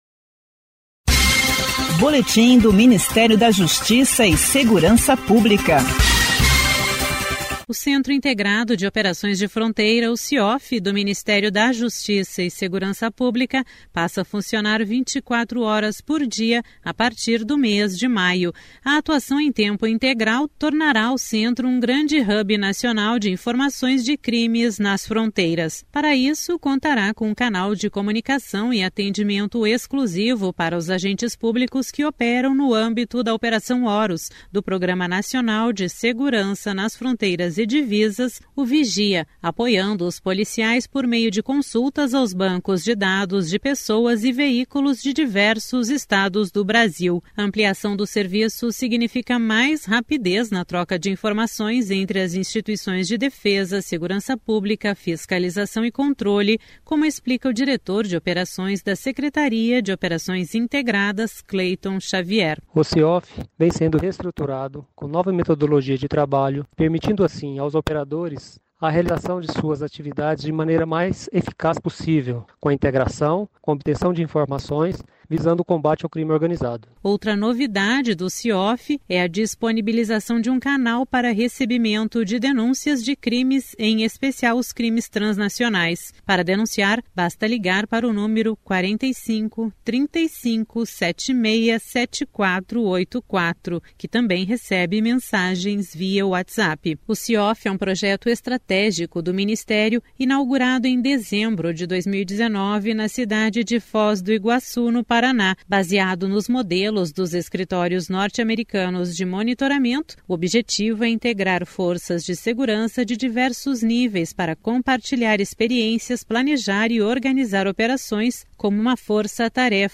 Boletins de rádio do MJSP — Ministério da Justiça e Segurança Pública